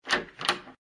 开门.mp3